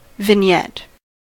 vignette: Wikimedia Commons US English Pronunciations
En-us-vignette.WAV